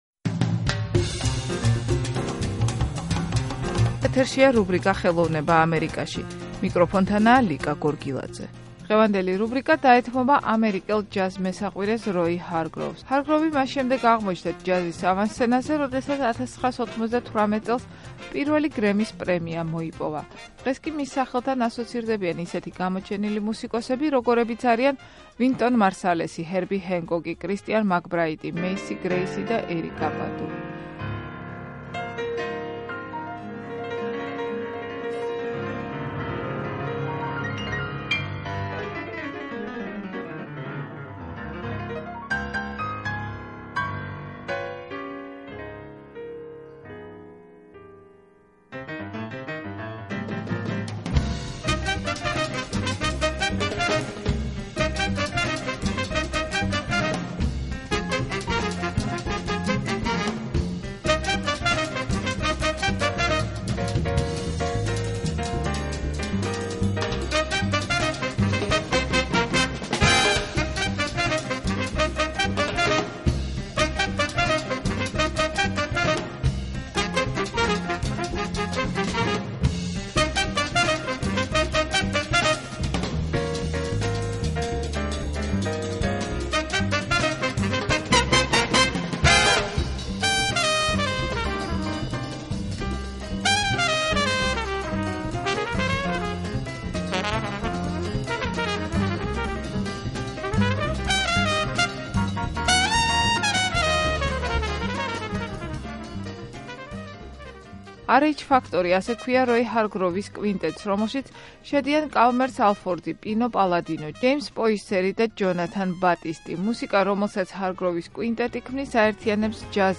ჰარგროვის კვინტეტი აერთინებს ჯაზის, ფანკის, ჰიპ ჰოპის, სოულისა და გოსპელის ელემენტებს